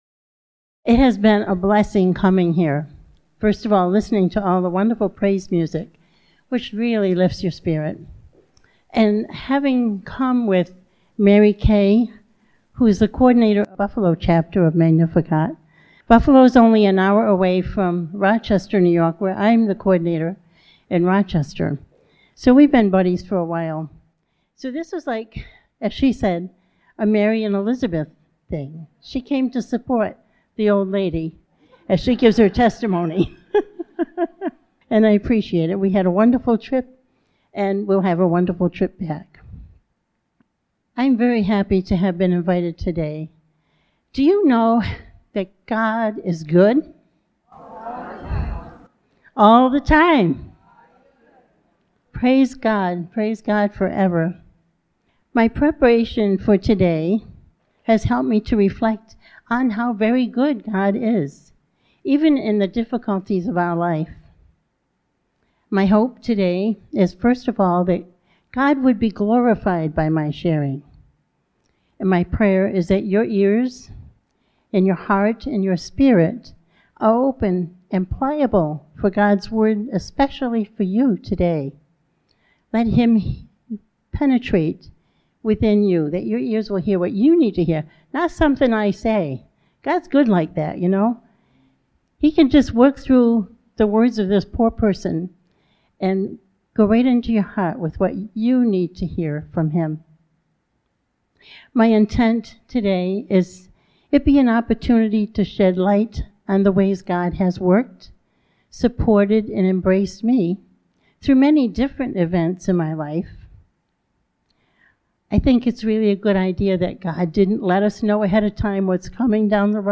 2024 June 1 Magnificat Morgantown witness